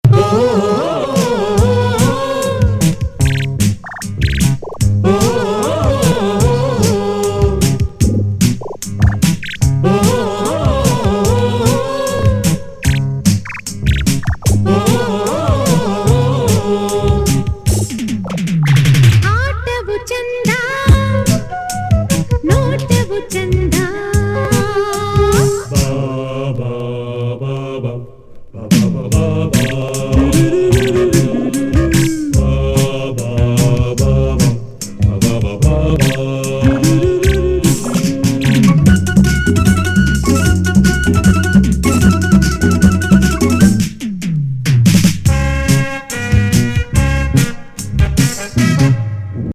ダンス音楽集!